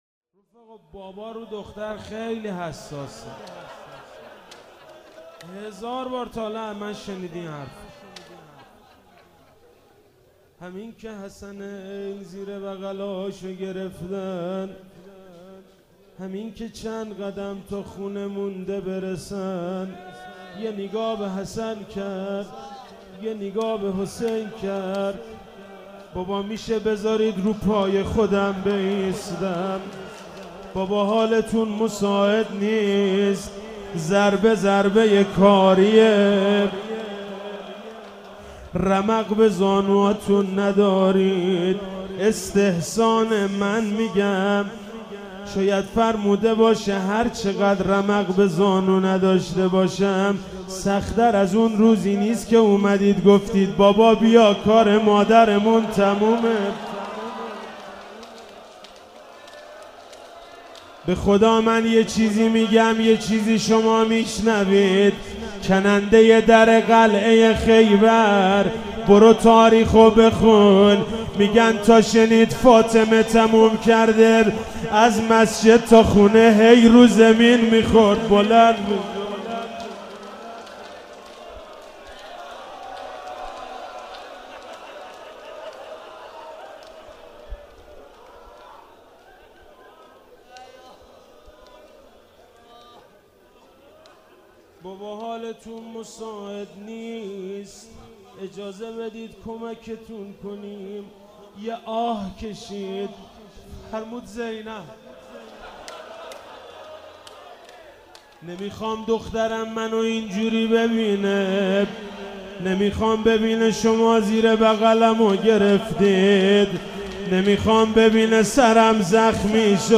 Madahi